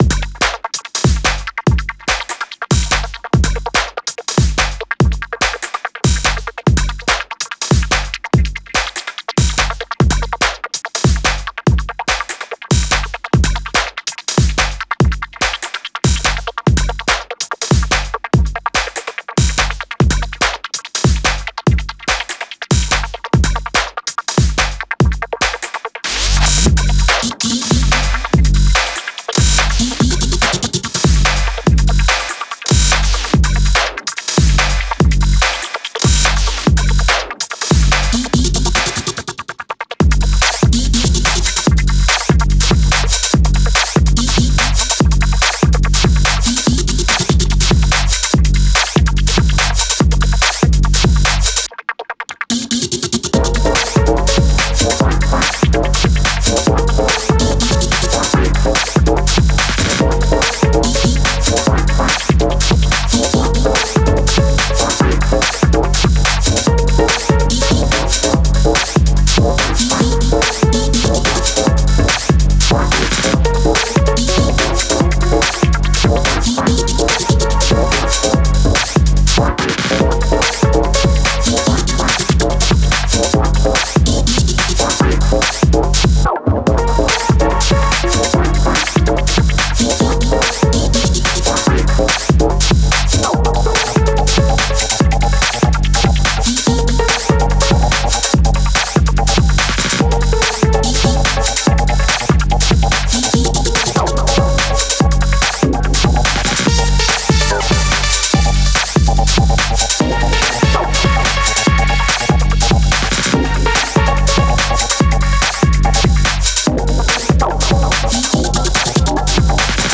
de pulsión electro-drexciana